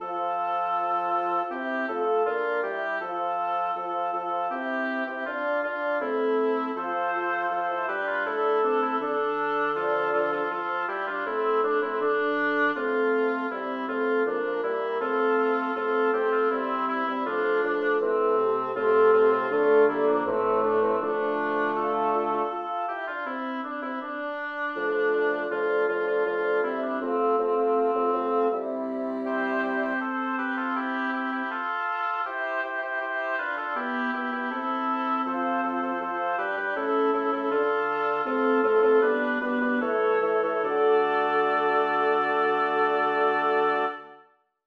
Title: Donna, tu sei sì bella Composer: Giaches de Wert Lyricist: Number of voices: 6vv Voicing: SSAATB Genre: Secular, Villanella, Canzonetta
Languages: Italian, Spanish Instruments: A cappella